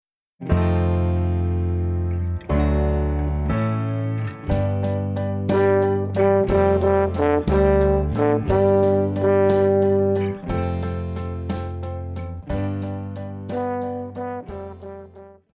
Pop
French Horn
Band
Classics,POP,Ballad
Instrumental
Rock,Ballad
Only backing